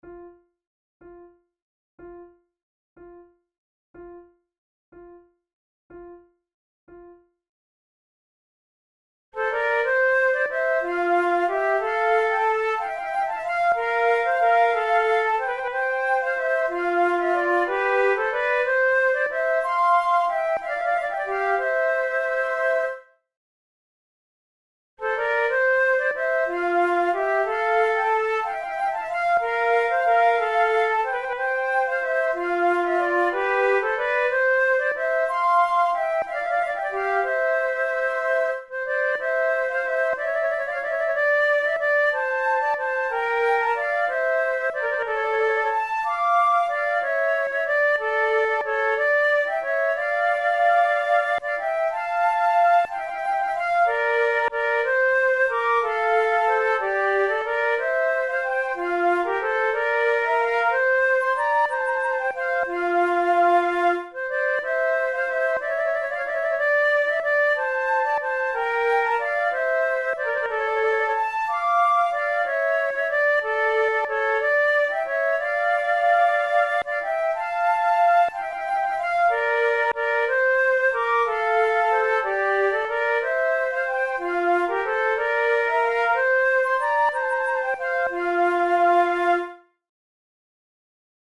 KeyF major
Tempo92 BPM
Play-along accompanimentMIDI (change tempo/key)
Baroque, Loures, Sonatas, Written for Flute